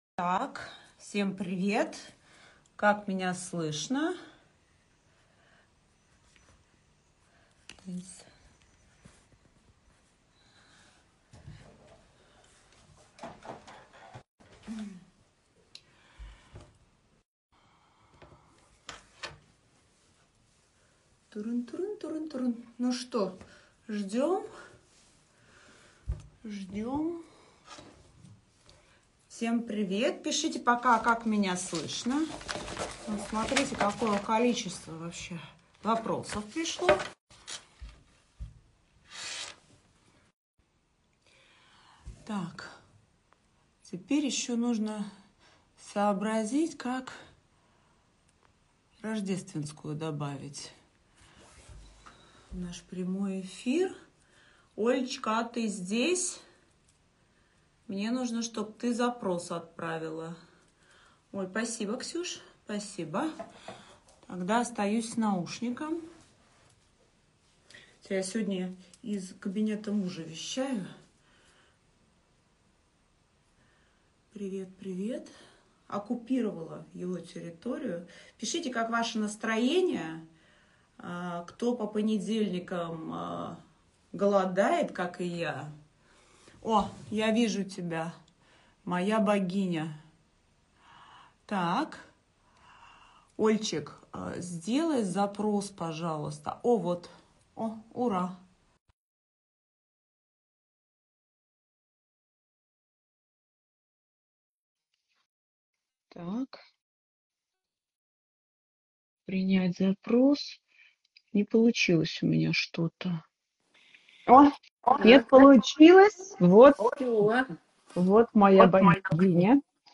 Источник: Персональное интервью